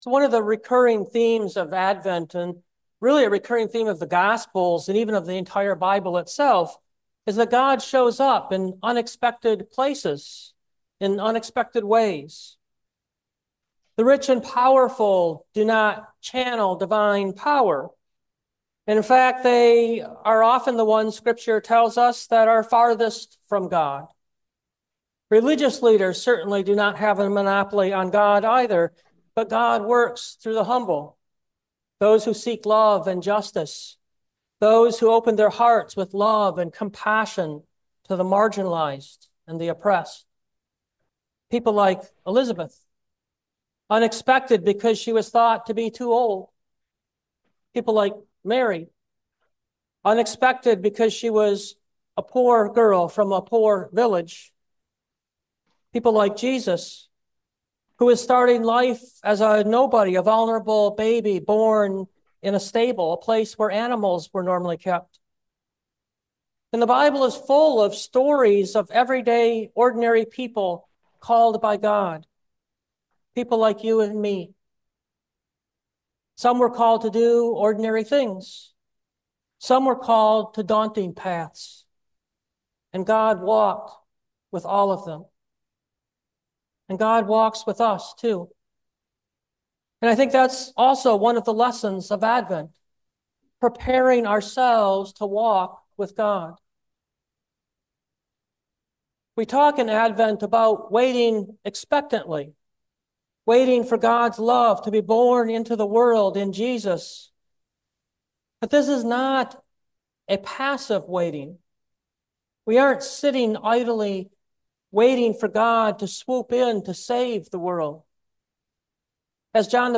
Trusting In God’s Love – Phoenix Community Church UCC